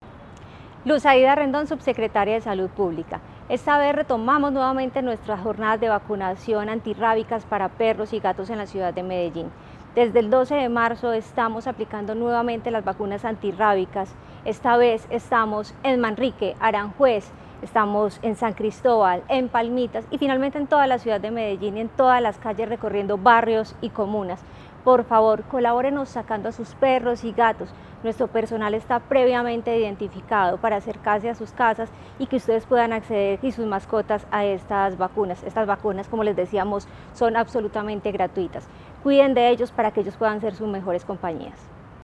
Audio Palabras de Luz Aida Rendón, subsecretaria de Salud Pública La Alcaldía de Medellín inició la jornada anual de vacunación antirrábica para perros y gatos en la ciudad.